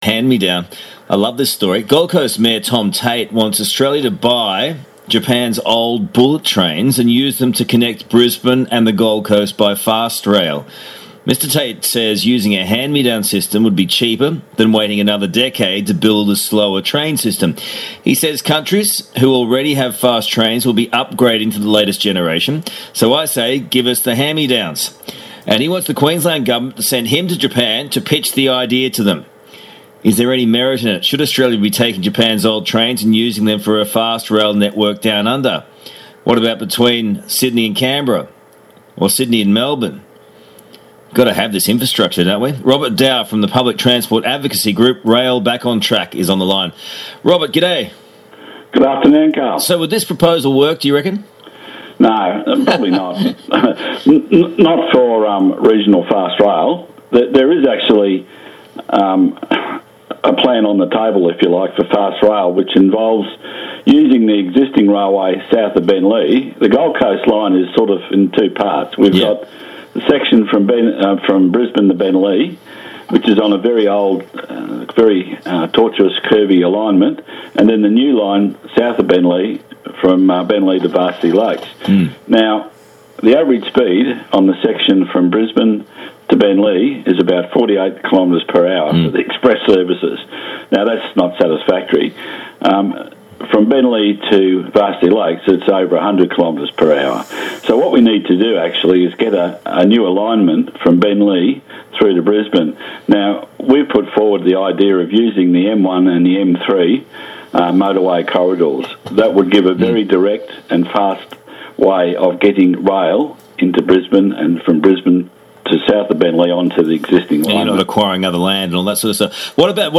Was a bit rushed but I managed to get the key points across, although more time would have been better.
Interview on radio 4BC/2GB 4 Nov 2019 Ben Fordham Live with host Karl Stefanovic